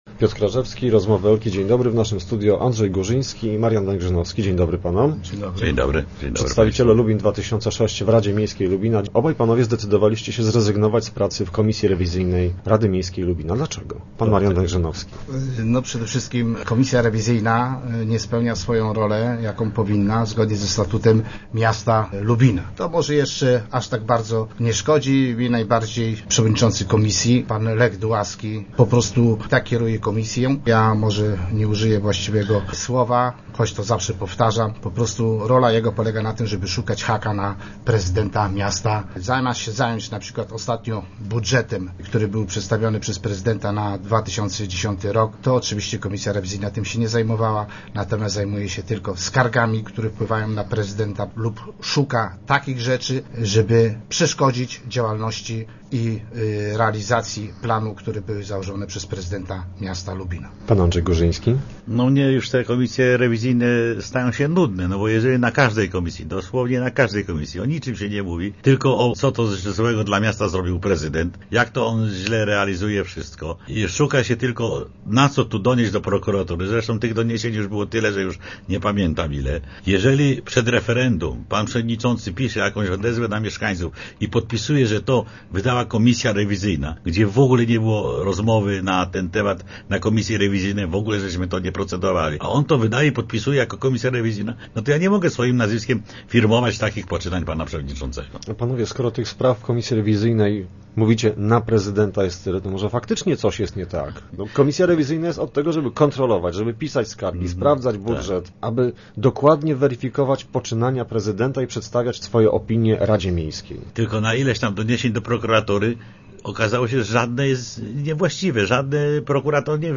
Radni Andrzej Górzyński i Marian Węgrzynowski, goście Rozmów Elki mówią, że konflikt załagodziłaby zmiana przewodniczącego komisji rewizyjnej.